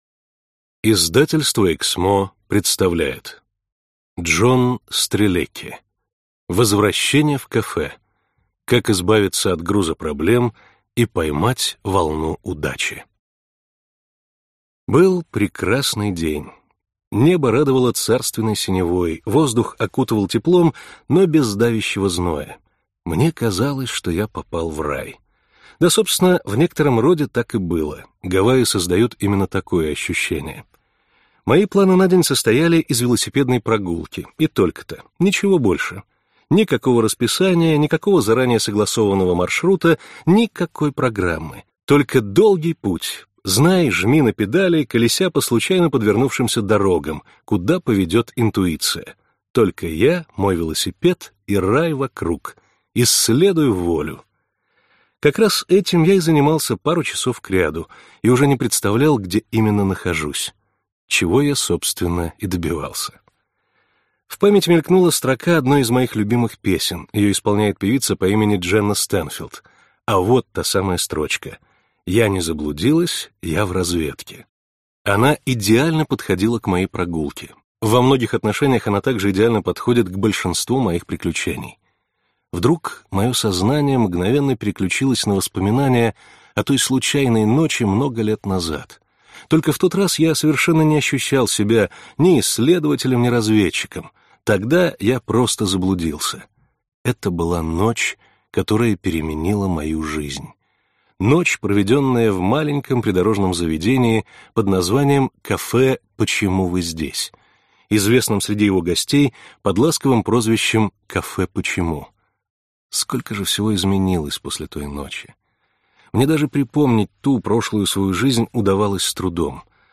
Аудиокнига Возвращение в кафе. Как избавиться от груза проблем и поймать волну удачи | Библиотека аудиокниг